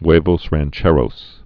(wāvōs răn-chĕrōs, hwā-, rän-)